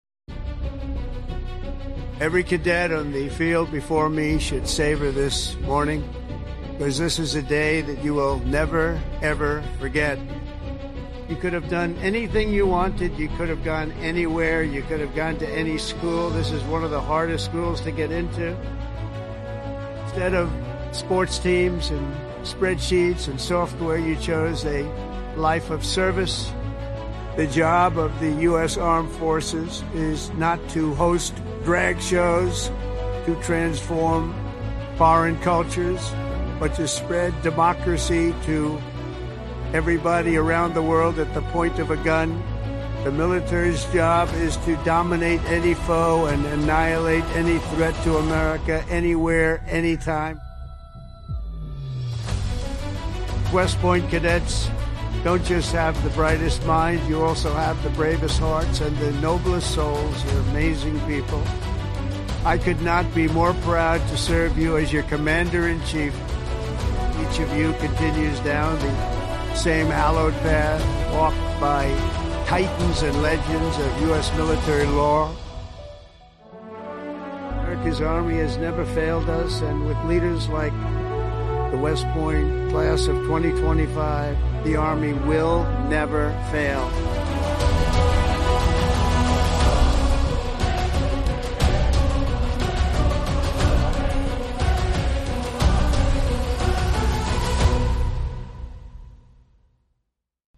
President Donald J. Trump Addresses the West Point Class of 2025
president-donald-j-trump-addresses-the-west-point-class-of-2025.mp3